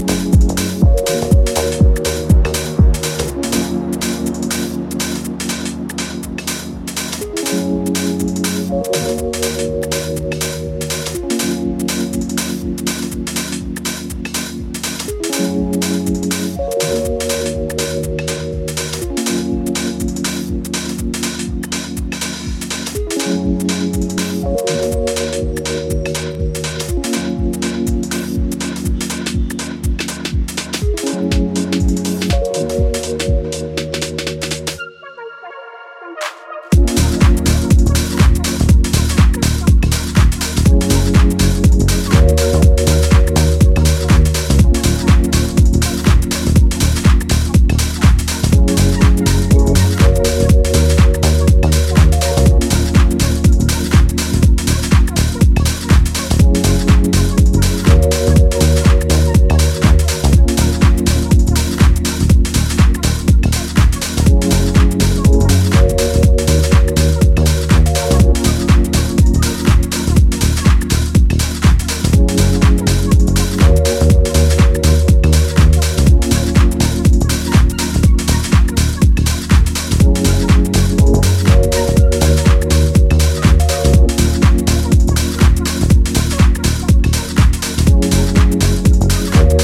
Killer deep house tunes
proper tracks that dig deep and work the floor.